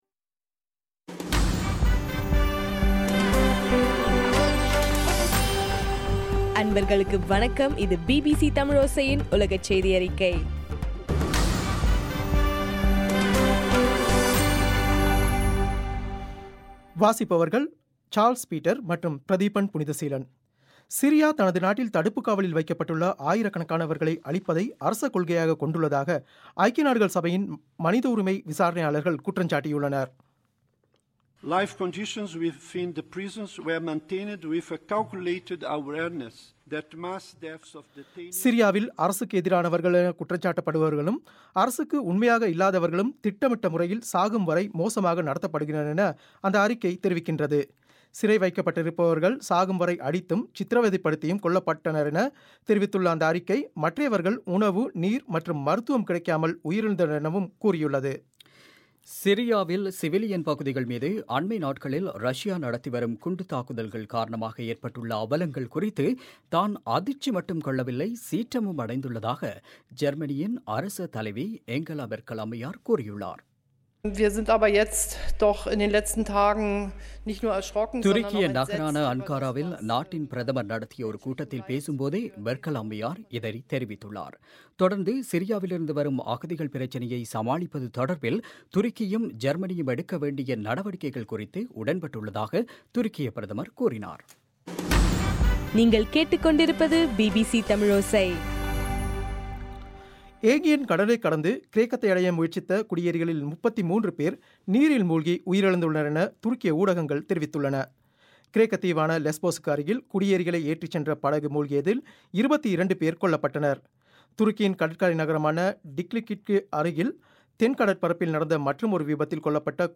இன்றைய (பிப் 8) தமிழோசை செய்தியறிக்கை